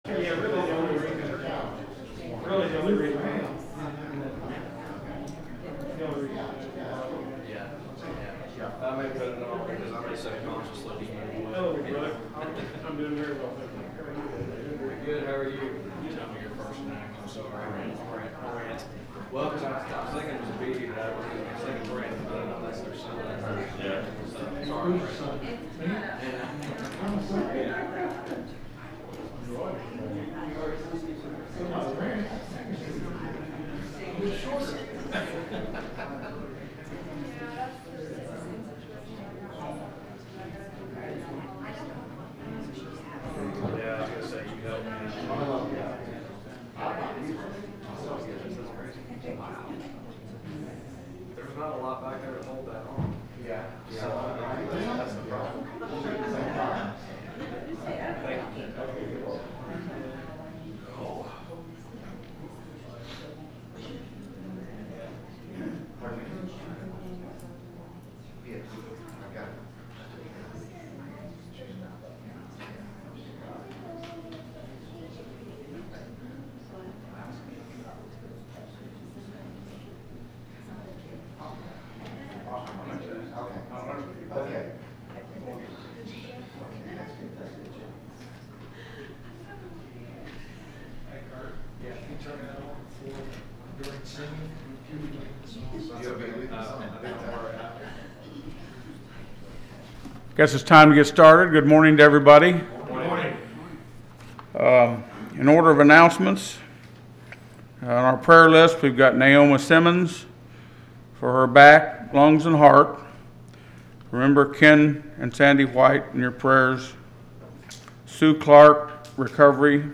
07-20-25 – Guest Speaker – SUNDAY AM WORSHIP | Harrisburg Church of Christ
Speaker: Guest Speaker
The sermon is from our live stream on 7/20/2025